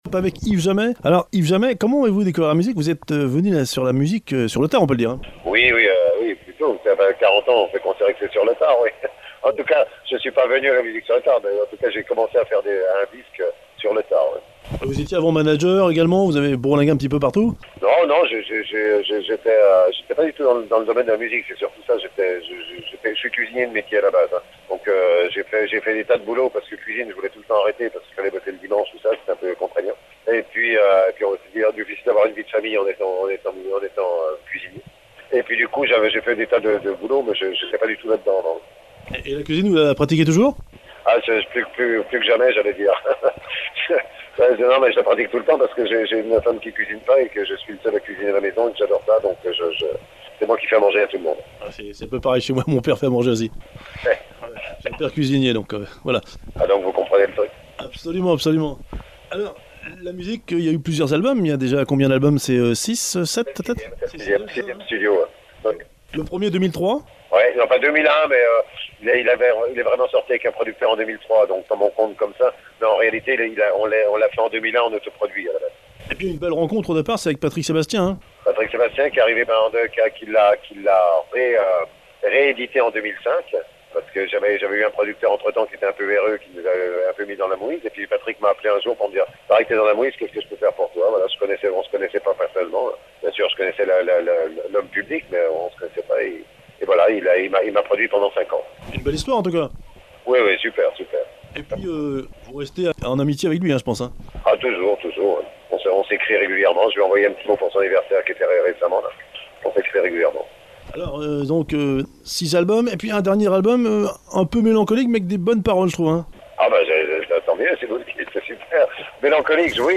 Interview d’Yves Jamait ( réalisée par téléphone juste après les attentats du 13 novembre 2015 à Paris)